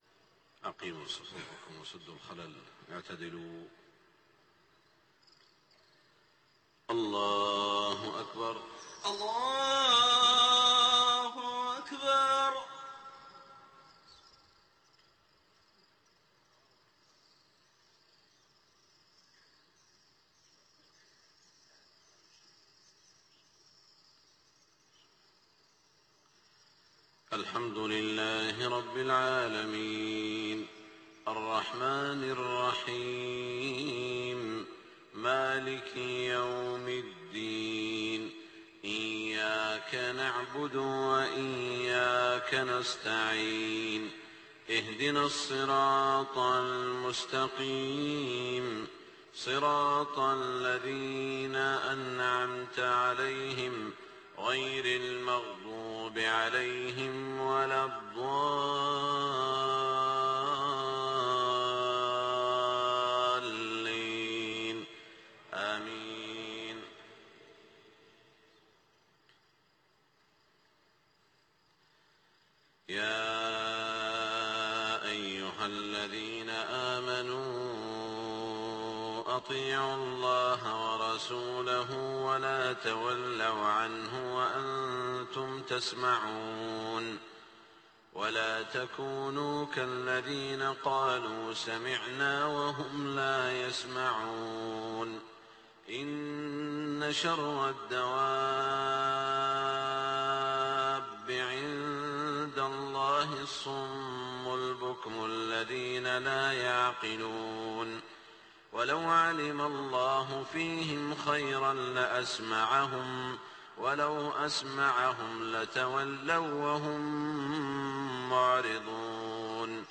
صلاة الفجر 8-3-1428هـ من سورة الأنفال 20-40 > 1428 🕋 > الفروض - تلاوات الحرمين